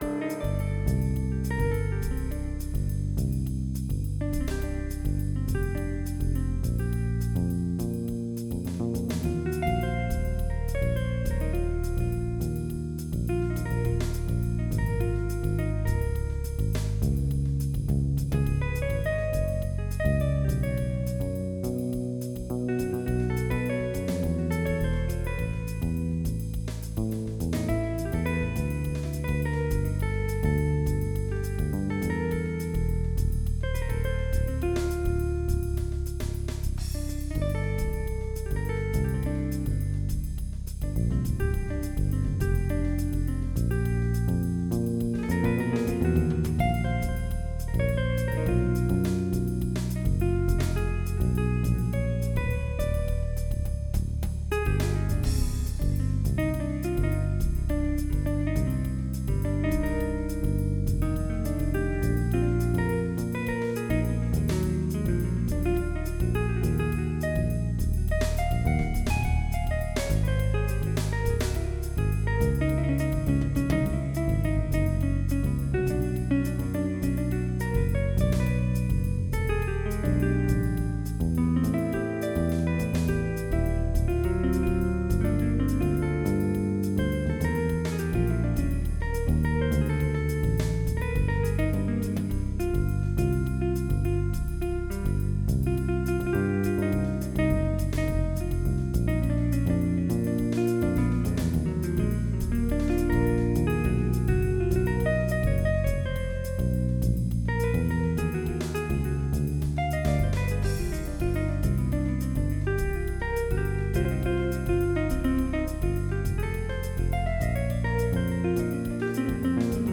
Jazz
MIDI Music File